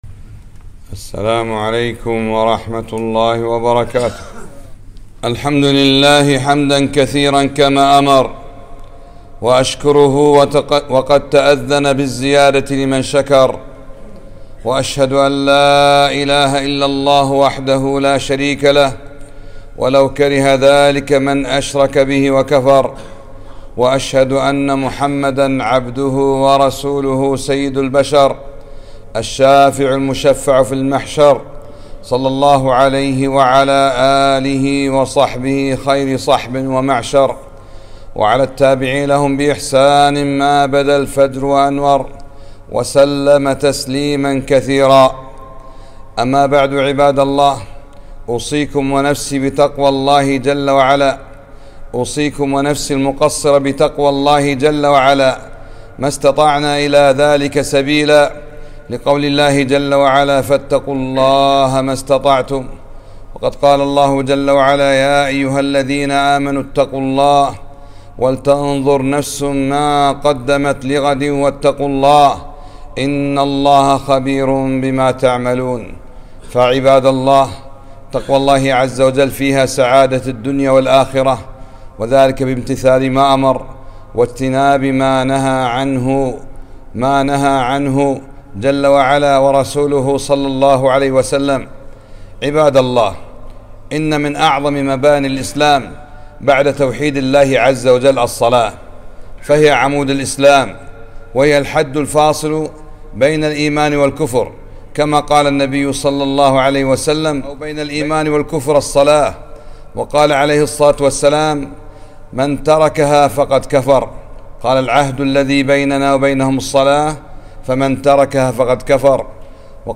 خطبة - حَافِظُوا عَلَى الصَّلَوَاتِ وَالصَّلَاةِ الْوُسْطَىٰ